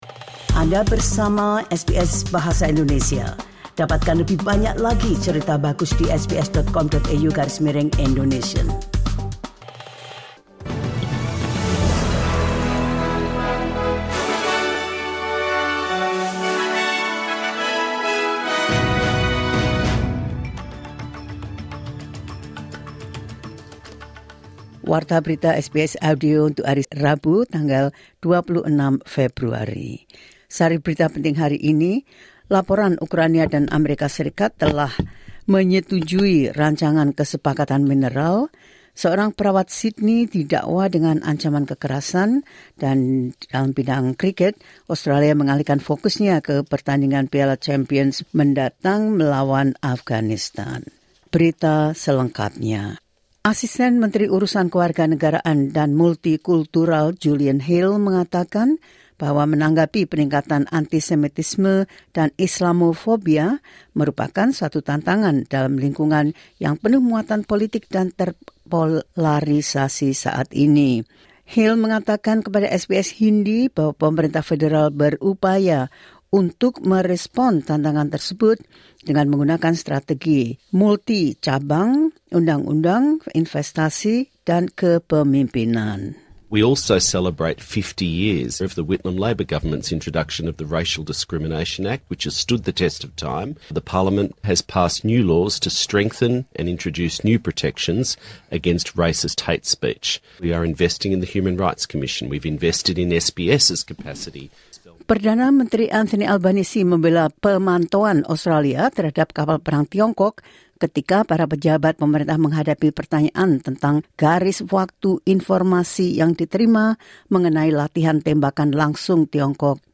Berita terkini SBS Audio Program Bahasa Indonesia – 26 Februari 2025